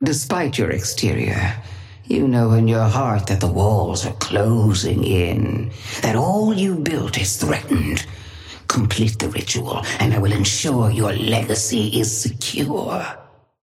Sapphire Flame voice line - Despite your exterior, you know in your heart that the walls are closing in, that all you built is threatened.
Patron_female_ally_wraith_start_08.mp3